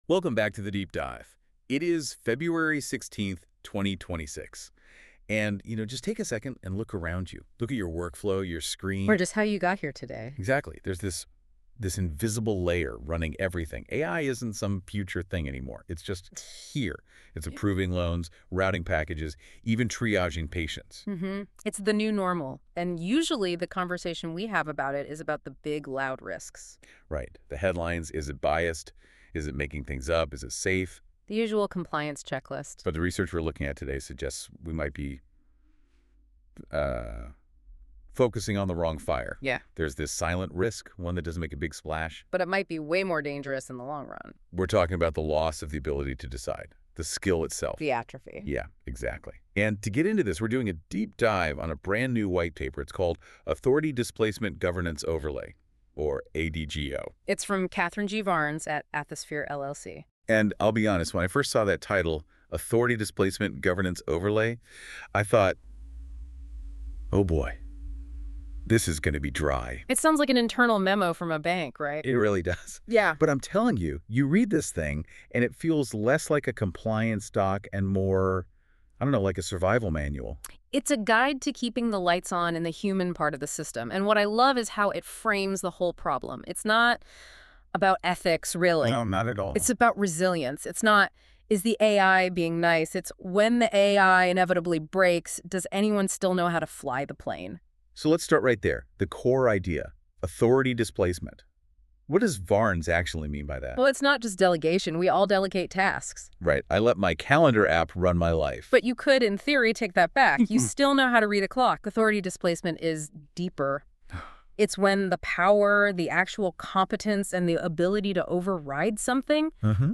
An AI-generated overview of the Authority Displacement Governance Overlay,